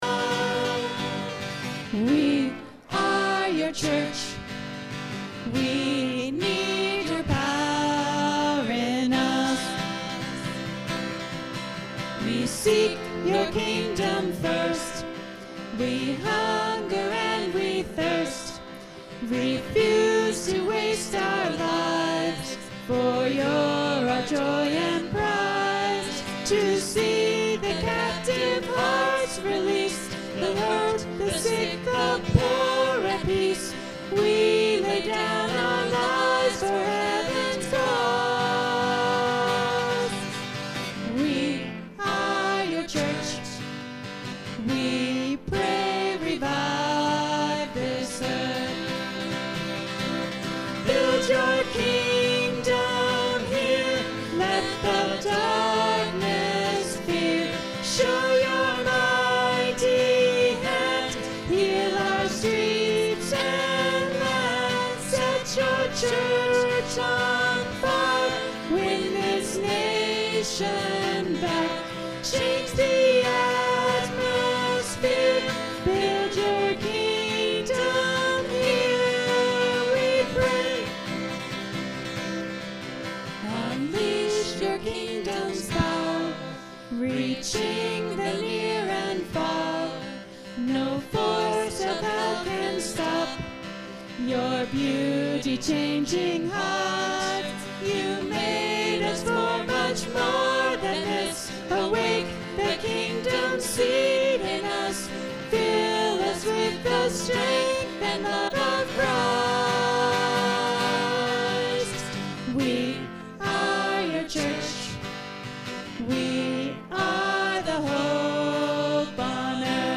Sermons – Page 29 of 43